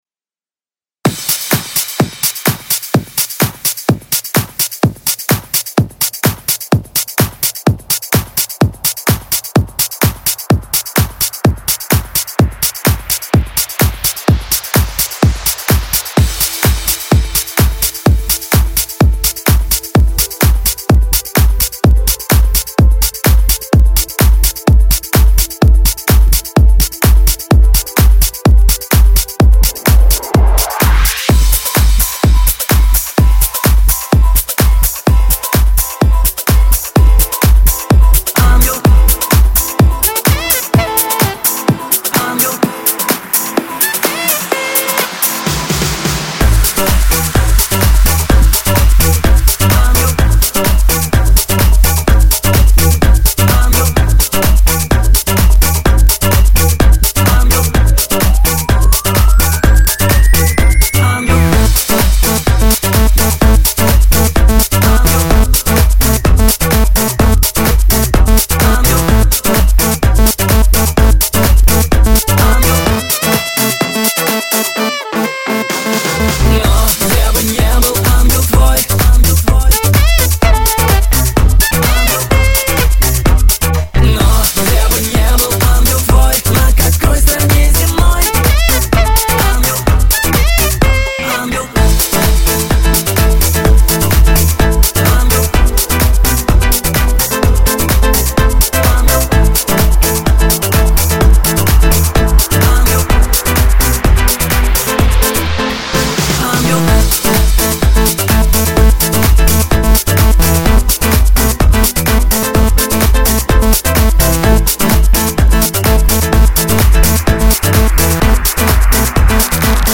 Club | [